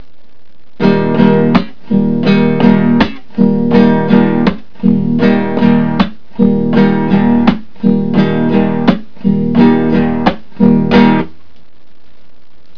I have played each slowly and made them into soundfiles, and then repeated the first example at a typical "La Bamba" tempo.
However, if you listen closely, you will hear a difference in the rhythm between the two.